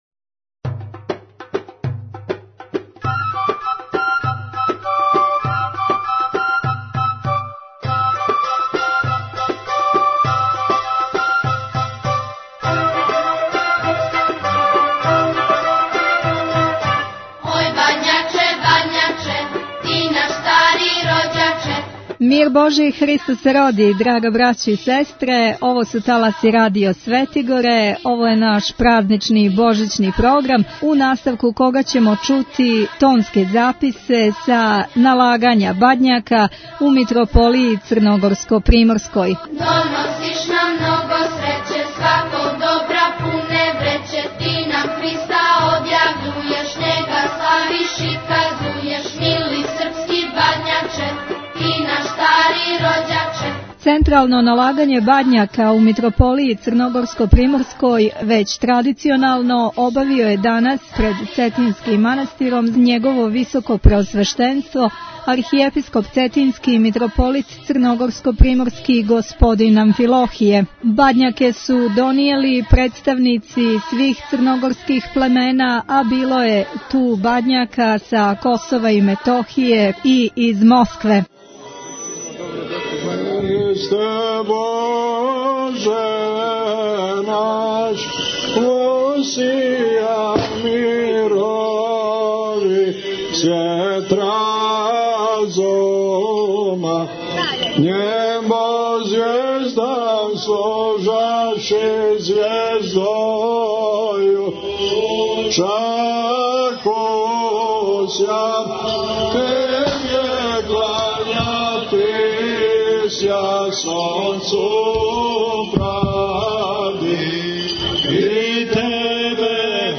Тонски записи са налагања Бадњака у Митрополији црногорско - приморској
Централно налагање Бадњака у Митрополији црногорско - приморској обавио је данас пред Цетињским манастиром Његово Високопреосвештенство Архиепископ Цетињски Митрополит црногорско - приморски Г. Амфилохије са свештенством у присуству великог броја вјерника.